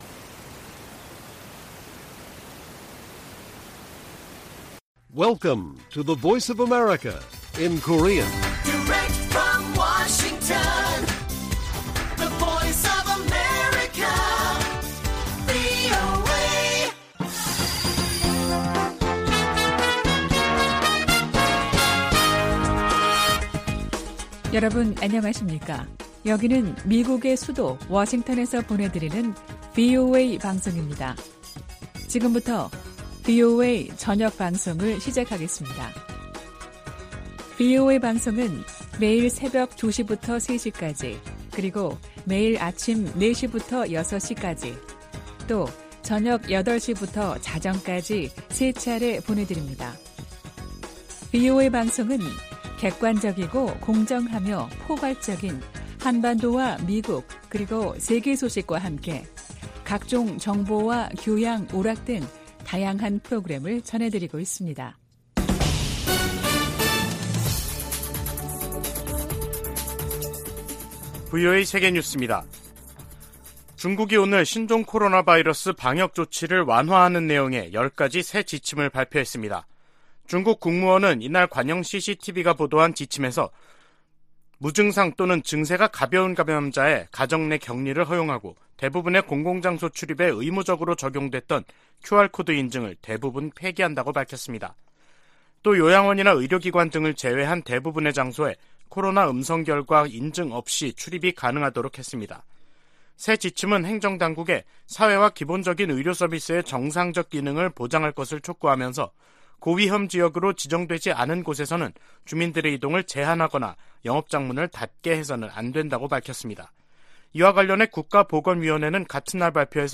VOA 한국어 간판 뉴스 프로그램 '뉴스 투데이', 2022년 12월 7일 1부 방송입니다. 국무부가 중국과 러시아에 유엔 안보리 대북 결의에 따른 의무를 이행하라고 거듭 촉구했습니다. 미국과 호주가 북한의 불법적인 핵과 탄도미사일 프로그램을 해결하겠다는 의지를 거듭 확인하고 국제사회에 유엔 안보리 결의 준수를 촉구했습니다.